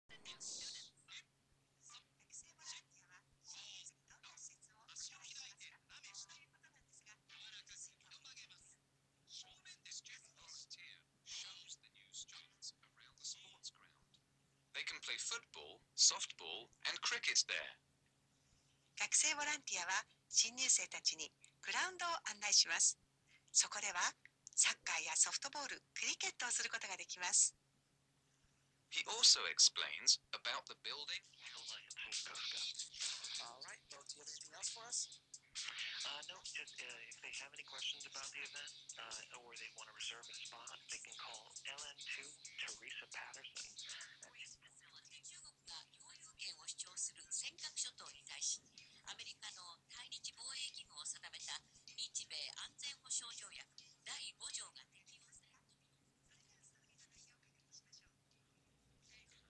途中の受信状況は場所により強く聞こえたり弱めだったり変化しましたが、話の内容は100%分かりました。
記事で製作したラジオを外部アンテナ無しで録音しています。
NHK第一、第二、AFN、TBS、文化放送の順に同調操作をしています。
NHK第一は音が小さいです。
mosfet_radio_outdoor.wma